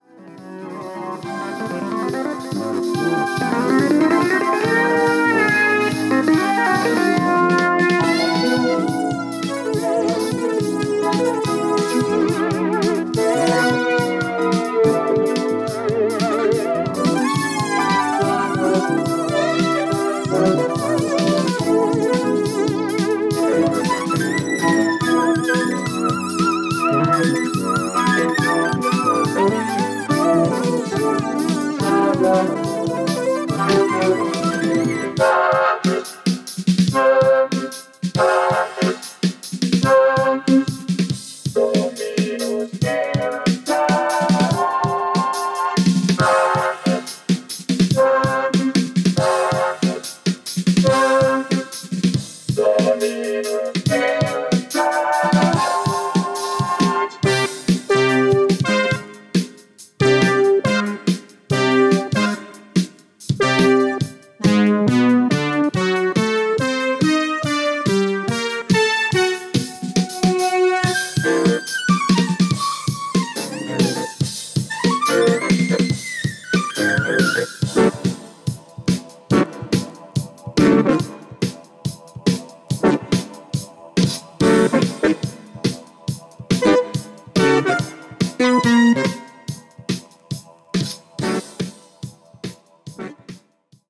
Synthesizer奏者の共演！！！